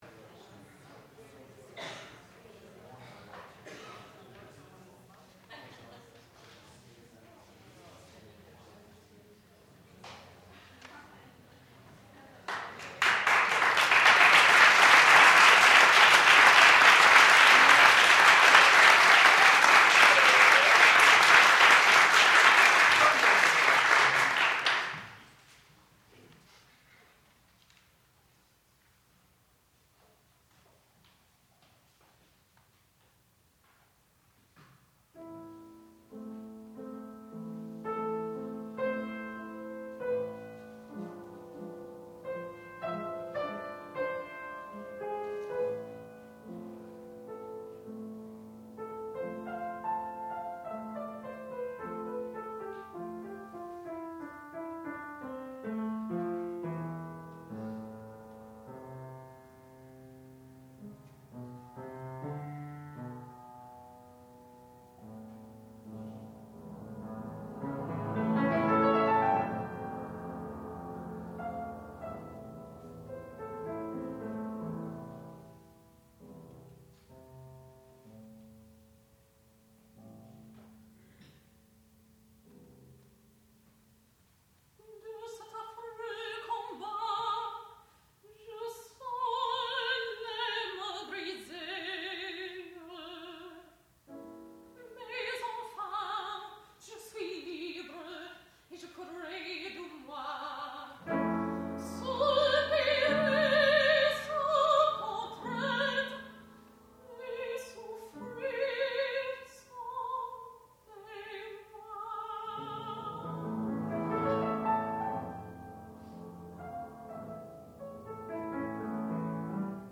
sound recording-musical
classical music
piano
mezzo-soprano
Junior Recital